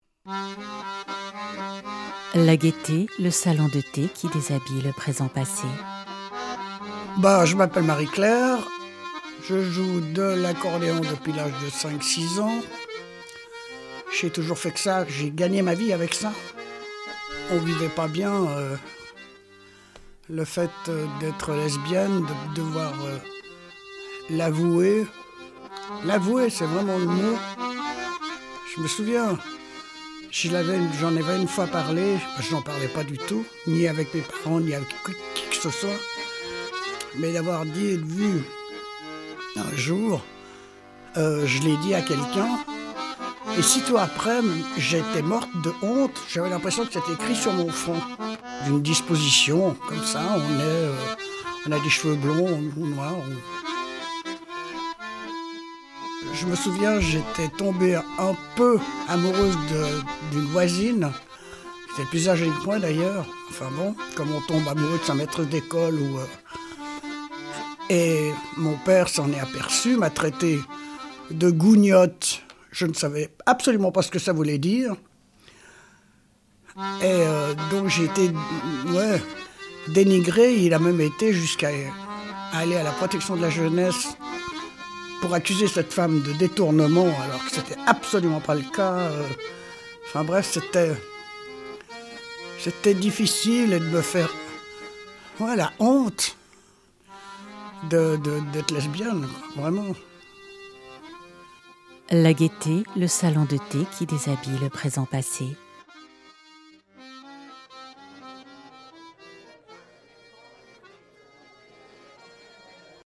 Série documentaire radiophonique (épisode 2 sur 6)